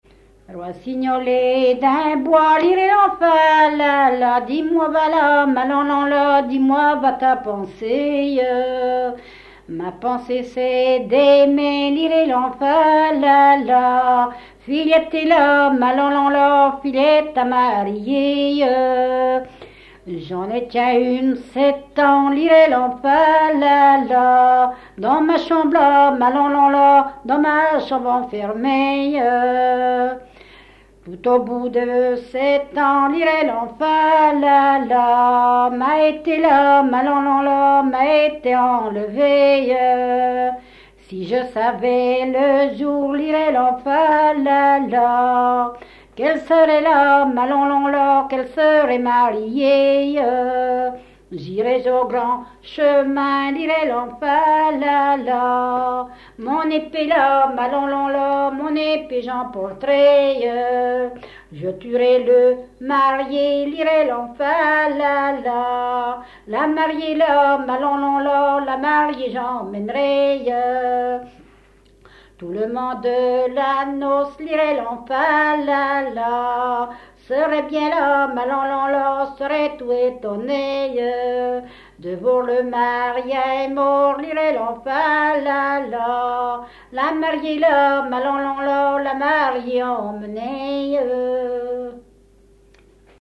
Base d'archives ethnographiques
Fonction d'après l'analyste danse : ronde ;
Genre laisse
Catégorie Pièce musicale inédite